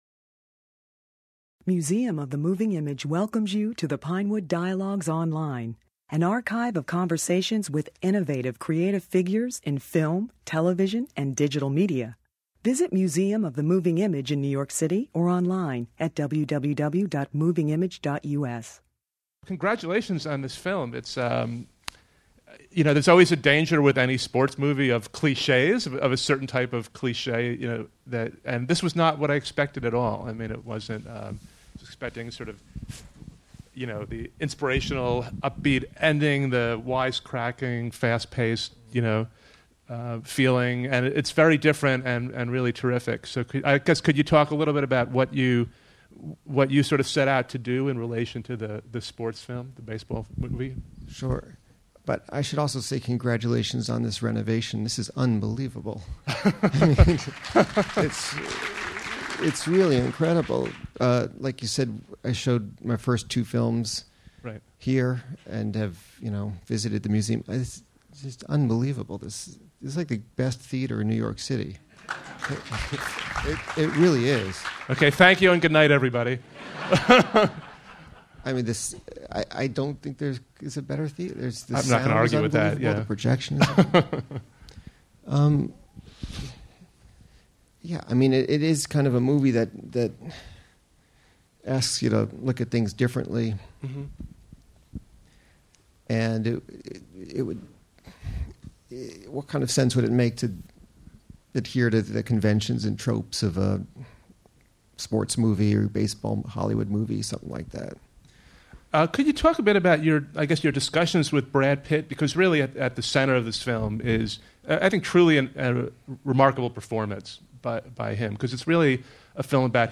In this conversation, Miller, who also presented Capote at the Museum, discusses the arduous process of getting Moneyball produced and how it is different than most other sports films.